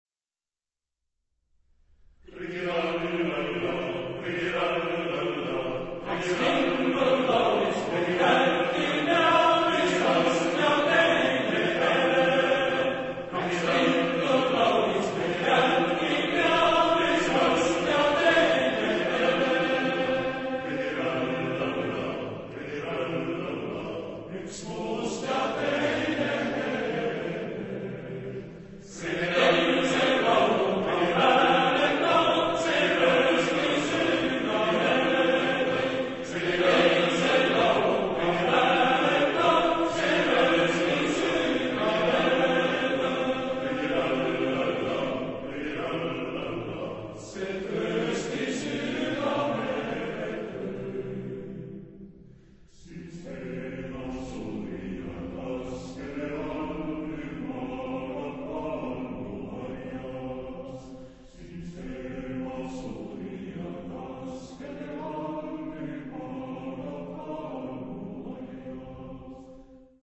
Genre-Style-Forme : Profane ; Pièce vocale
Type de choeur : TTBB  (4 voix égales d'hommes )
Tonalité : fa majeur